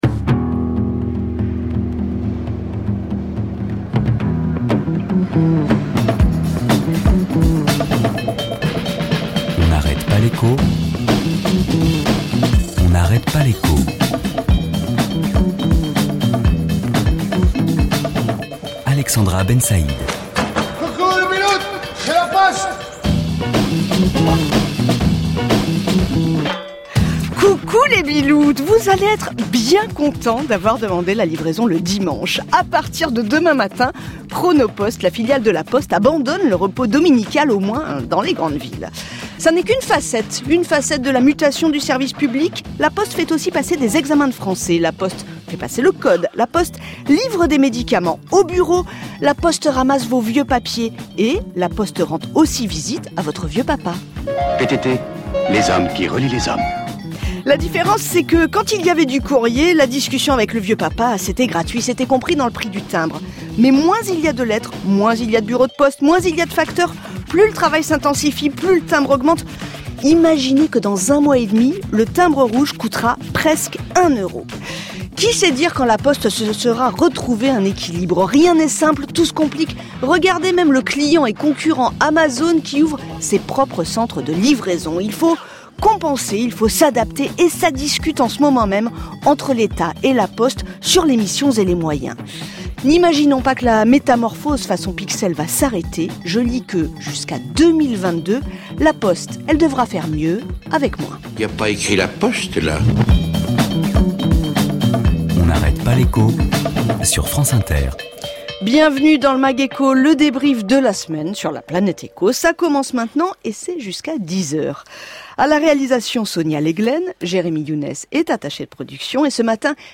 PS: Ecouter Le reportage audio (mp3) avec les controleurs PE FranceInter à partir de 23min 40